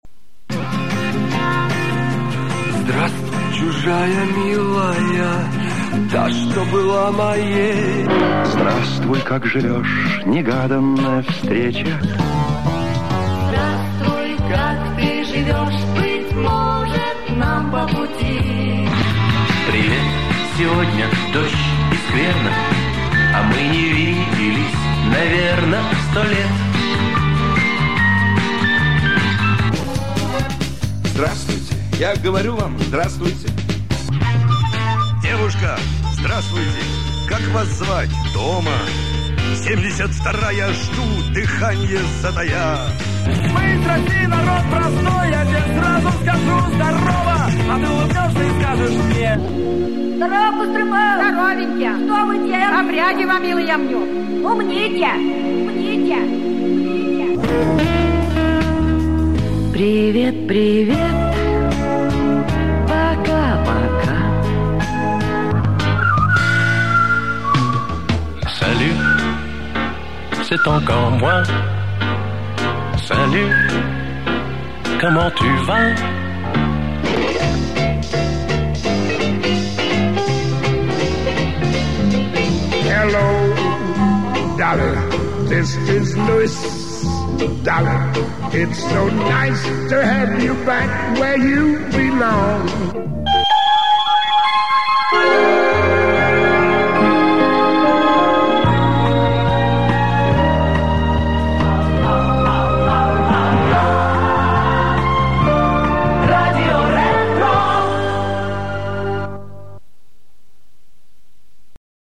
Предлагаю вашему вниманию музыкальные заставки придумывал и записывал сам на магнитоле "Panasonic CT980". Оцифровка с кассет.
заставки